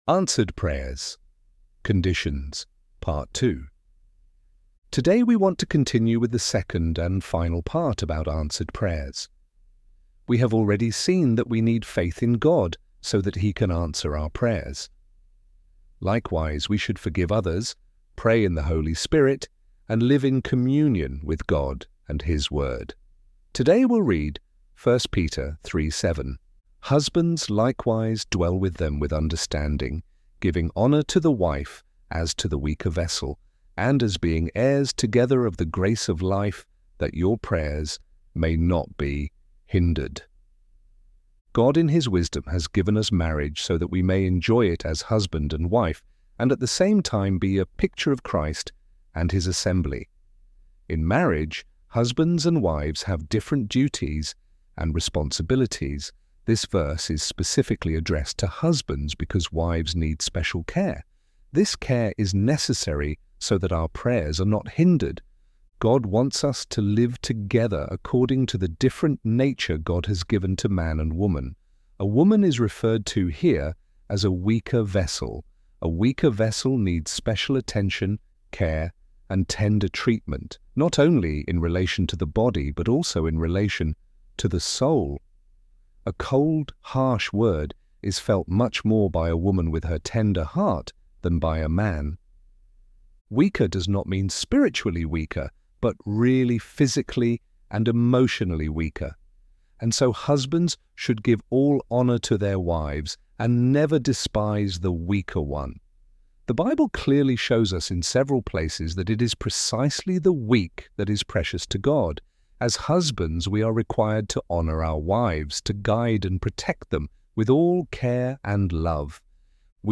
ElevenLabs_Answered_Prayers_part_2.mp3